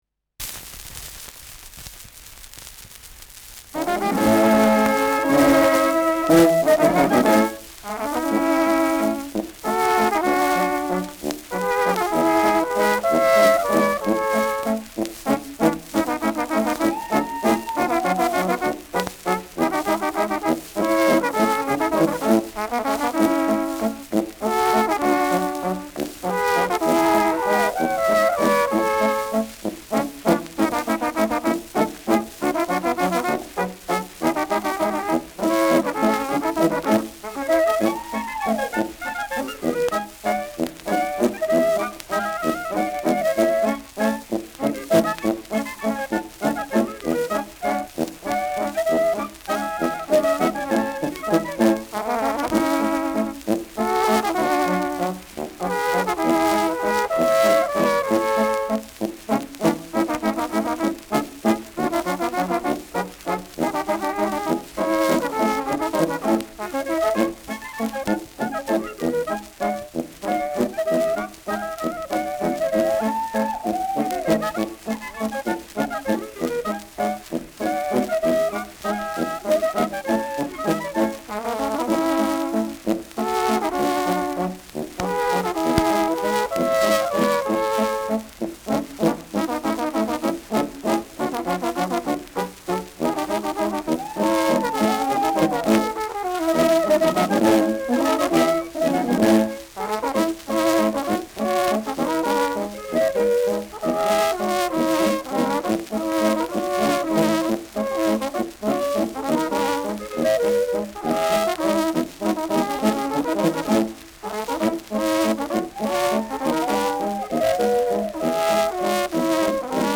Schellackplatte
Mit Juchzern.